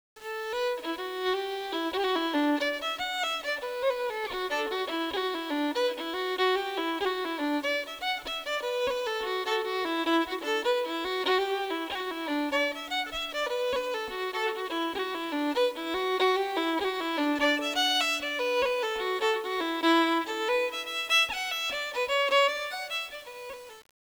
Irish Music
violin
violin.wav